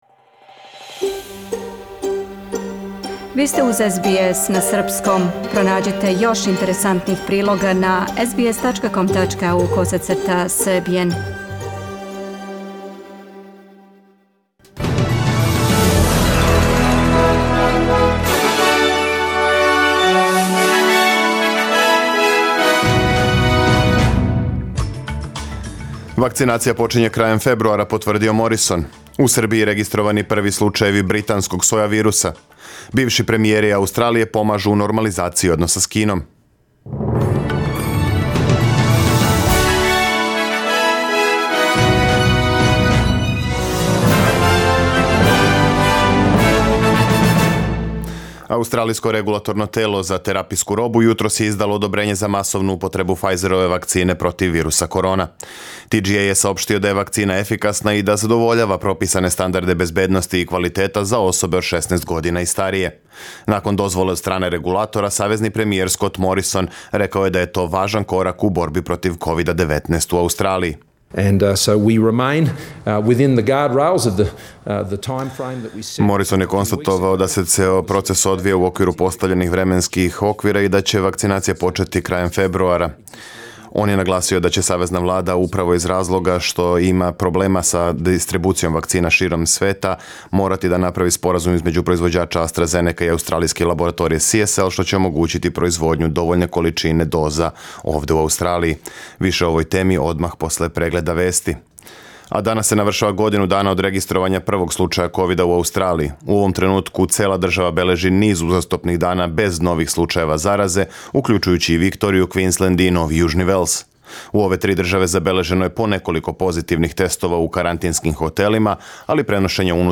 Serbian News Bulletin Source: SBS Serbian